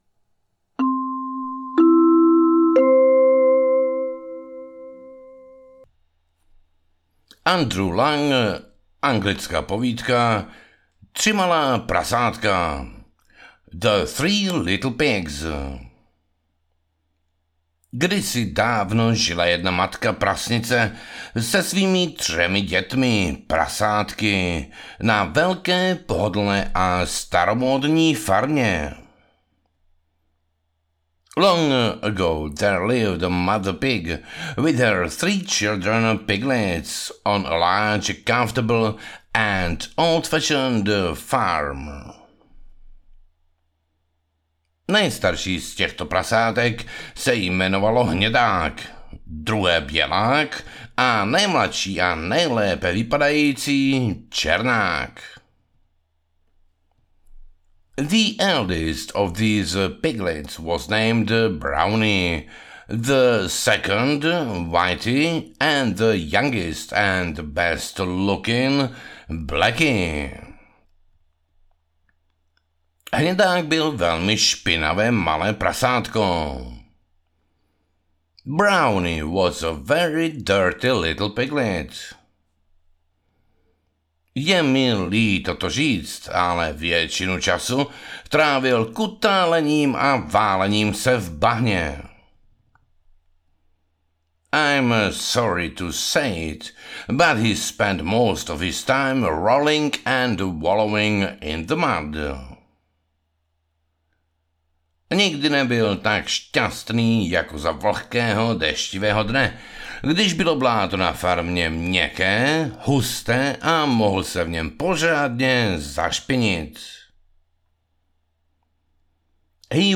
Povídky a věty 1, Anglicky, Česky audiokniha
Ukázka z knihy
povidky-a-vety-1-anglicky-cesky-audiokniha